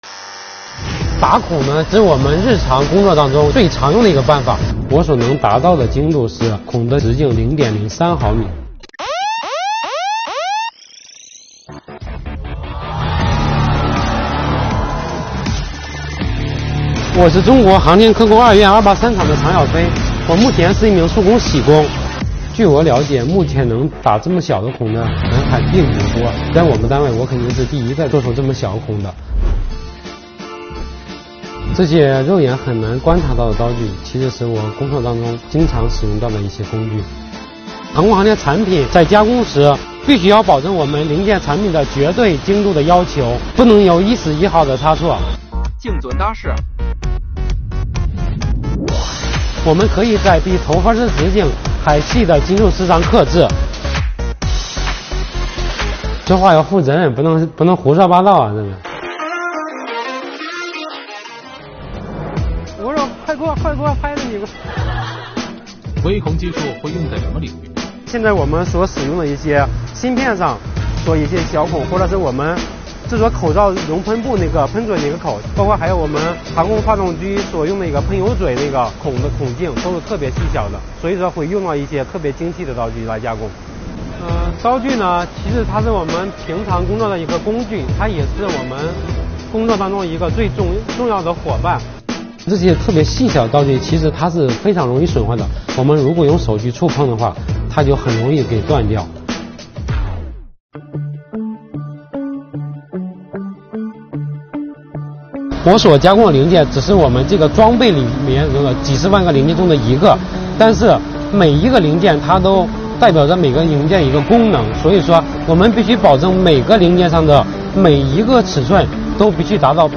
由央视频、全总新闻中心、CGTN联合推出的“劳动最光荣——前方高能”原创匠心微纪录片致敬这个时代最美丽的人，向国人及世界展现奋斗在我国各行各业里平凡却闪光的人物，传递中国精神与中国力量，弘扬劳模精神、劳动精神、工匠精神！